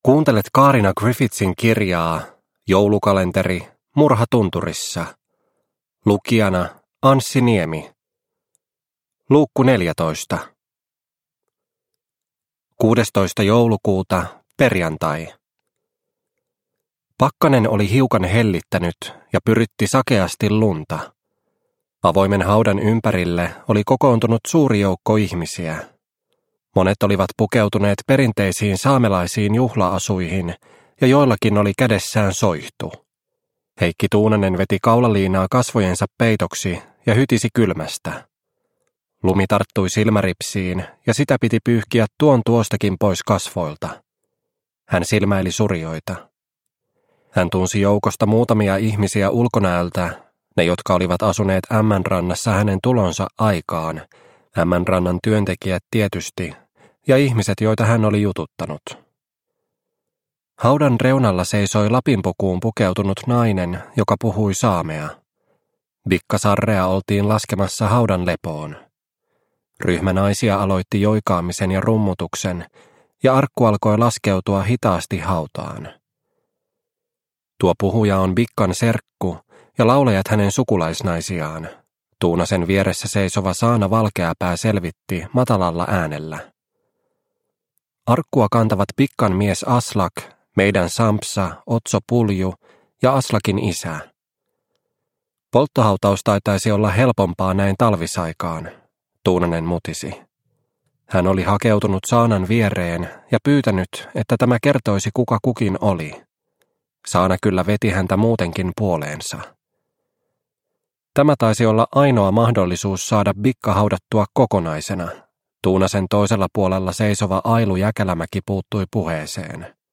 Murha tunturissa - Osa 14 – Ljudbok – Laddas ner